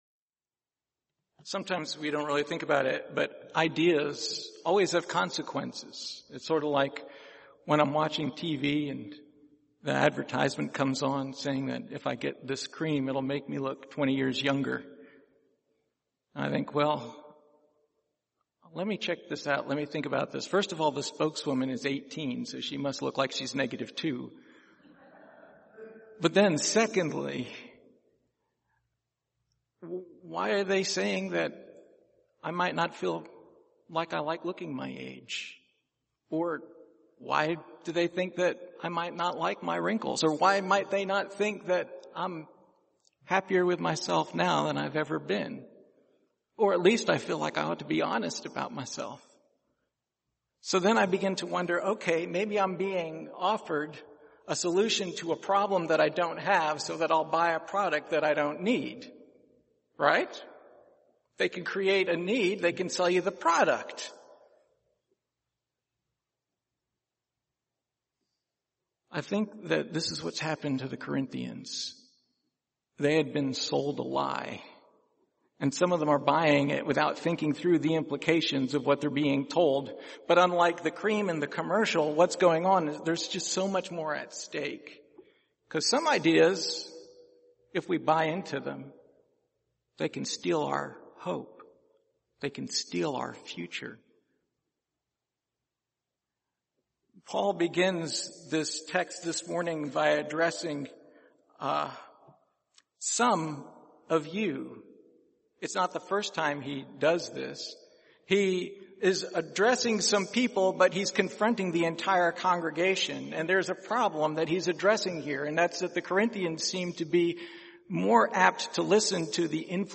Passage: 1 Corinthians 15:12-19 Service Type: Sunday Morning